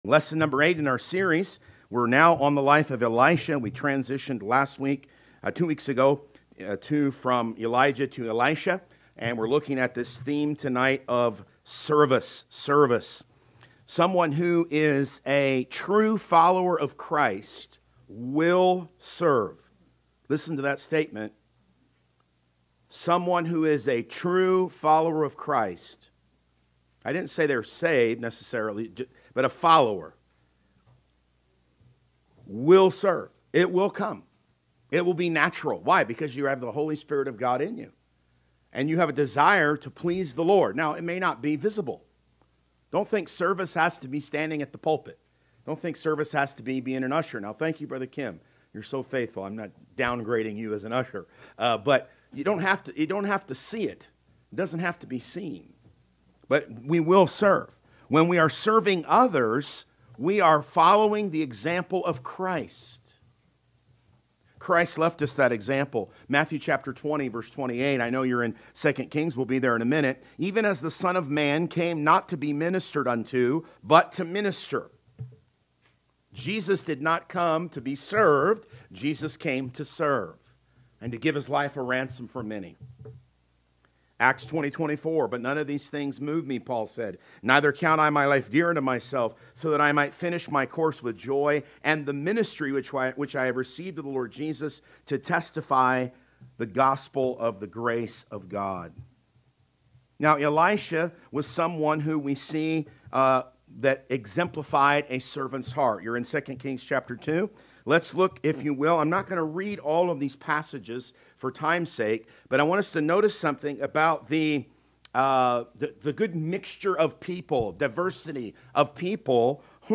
Sermons | Anchor Baptist Church
Service Audio